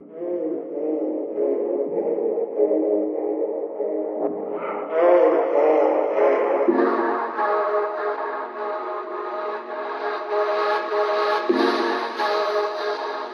HIGH 144BPM - FUSION.wav